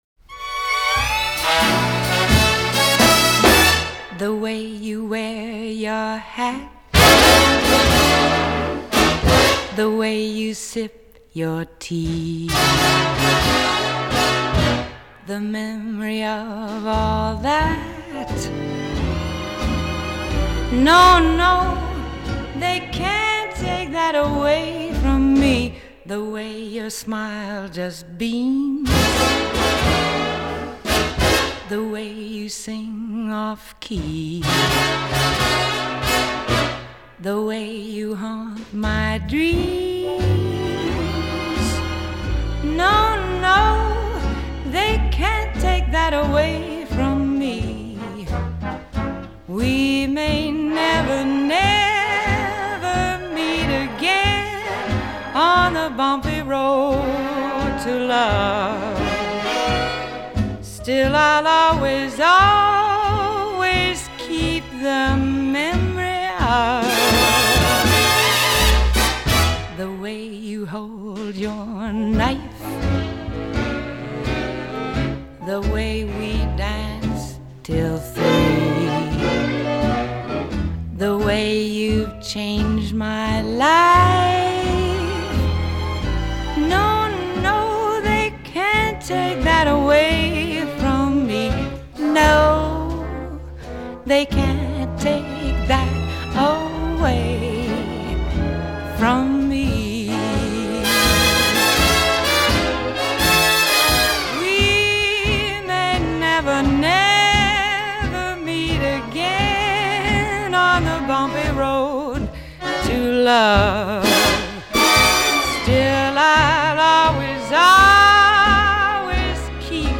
И немного для вечернего настроения джазовой музыки.